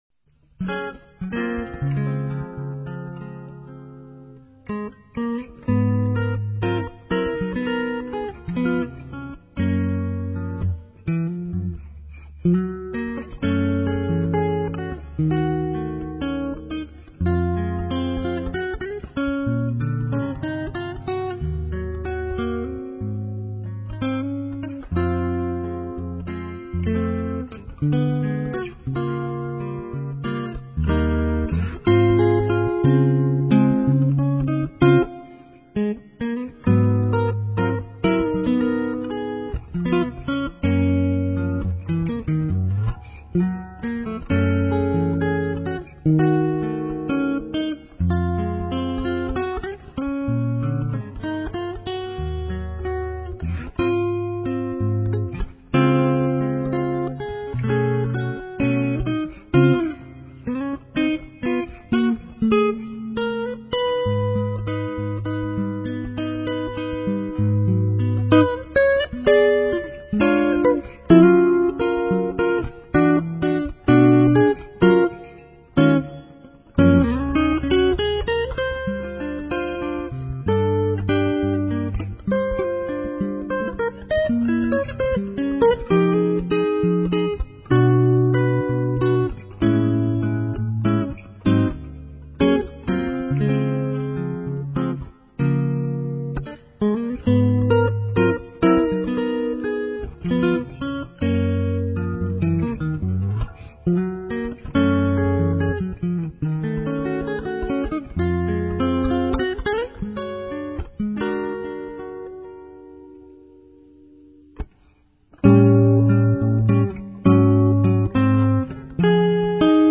本来、唄付きの曲をアコースティックギターによるインストにしたものです。